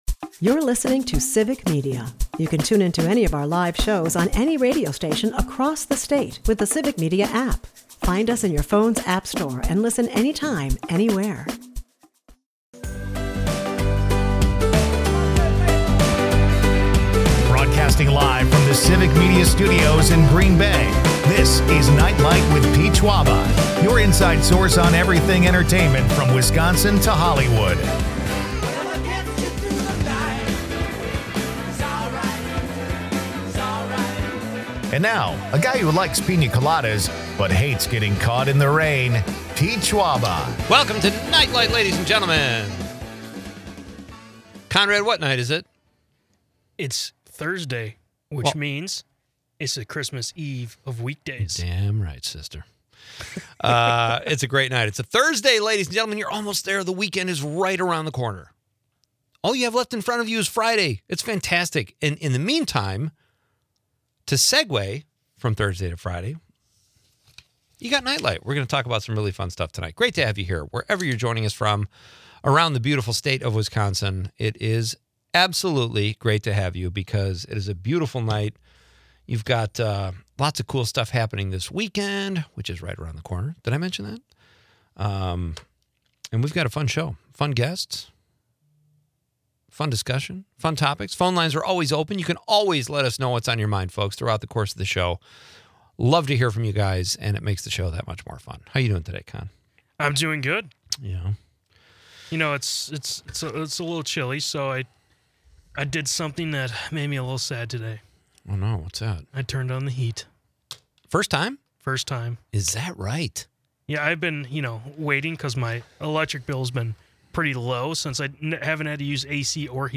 As the show delves into personal growth, listeners share what they wish they were better at, from cooking to playing guitar. With a backdrop of spirited banter on football, the Wisconsin Book Festival, and the Thrasher Opera House's haunted tales, the episode is a blend of fun, nostalgia, and community spirit.
Leave the stress of the day behind with entertainment news, comedy and quirky Wisconsin.